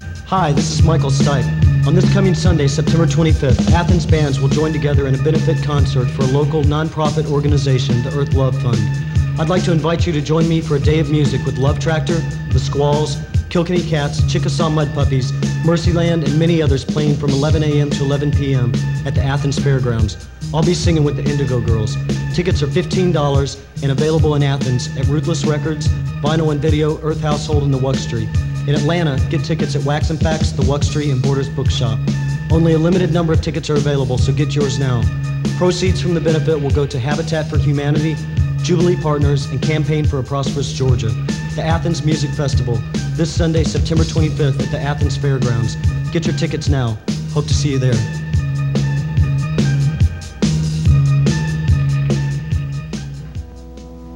(acoustic duo show)
01. advert for the show (michael stipe) (0:54)